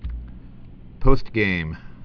(pōstgām)